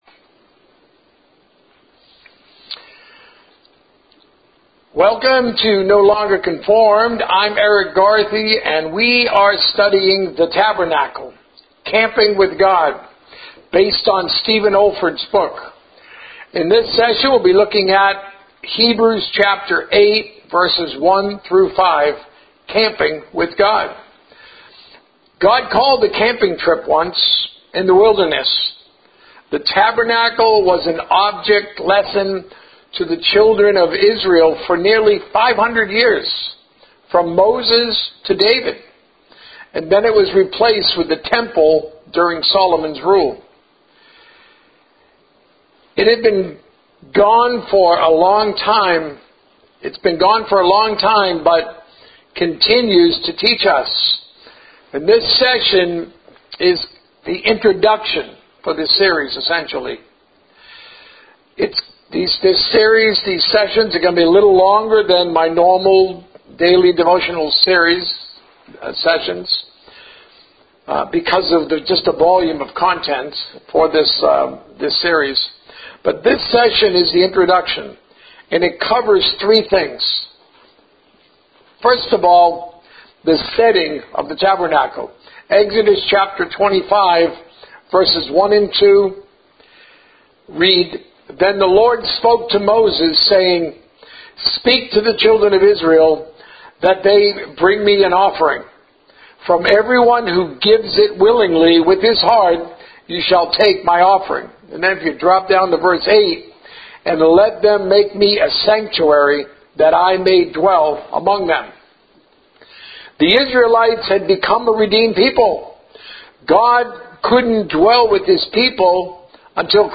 A message from the series "The Tabernacle."